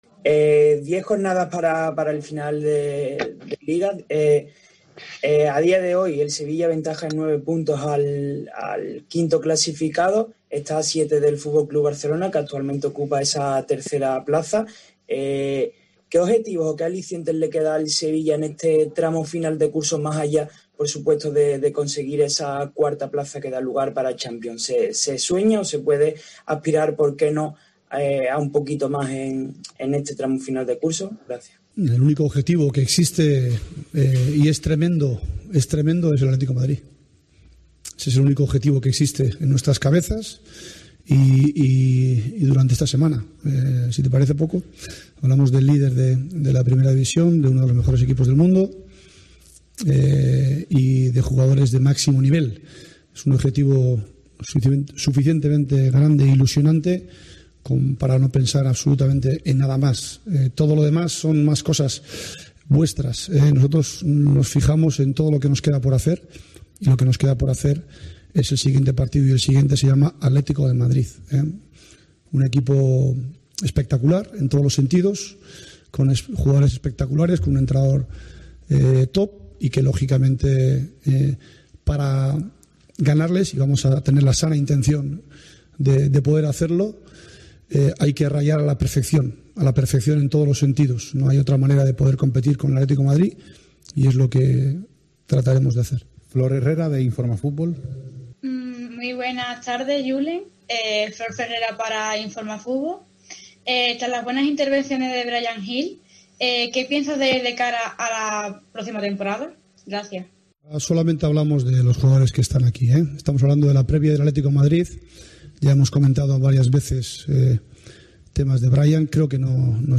LOPETEGUI, EN LA RUEDA DE PRENSA PREVIA AL CHOQUE ANTE EL ATLETI